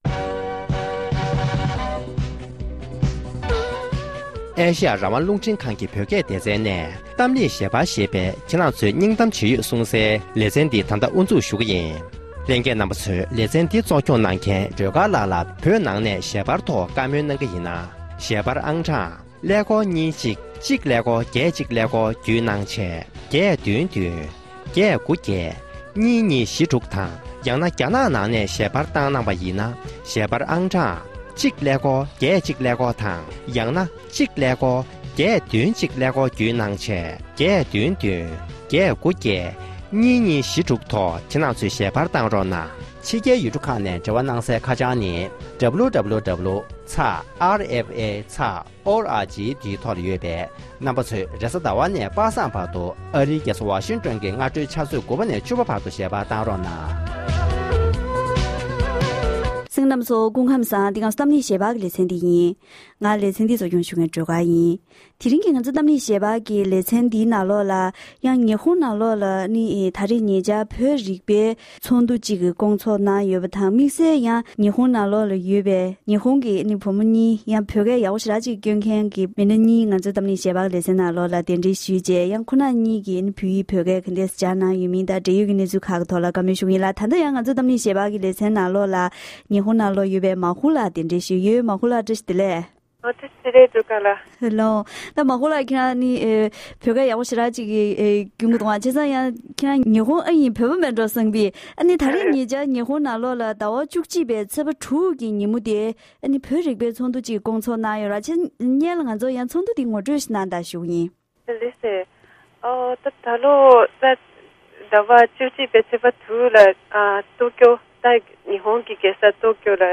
གཏམ་གླེང་ཞལ་པར་